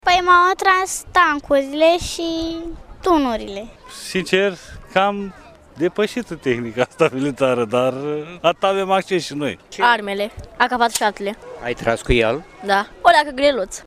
La Iaşi, Ziua Forțelor Terestre din România esa fost marcată  printr-o expoziție de tehnică militară.
Numeroși vizitatori, mai ales copii, au mers la evenimentul de astăzi: